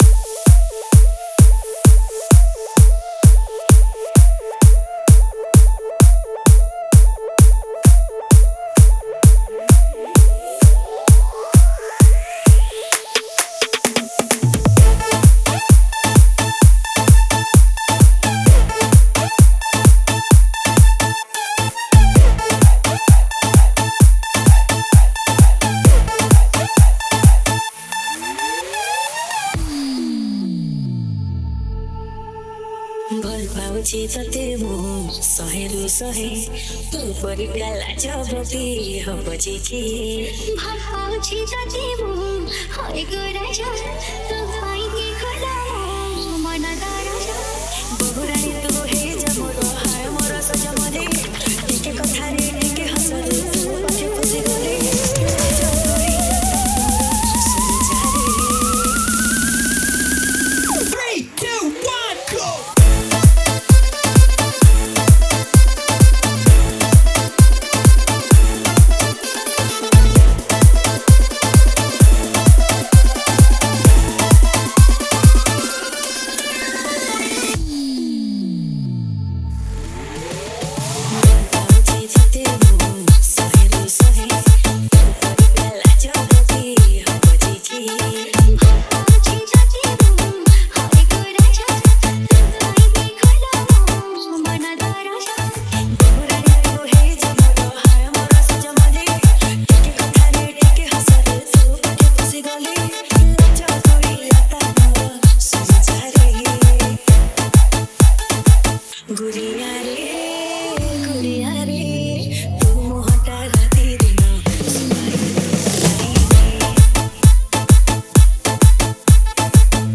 Romantic Love Dj Remix Songs Download